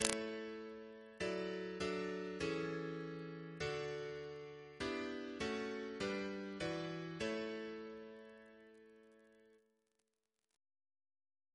Single chant in A Composer: Gerre Hancock (1934-2012) Reference psalters: ACP: 2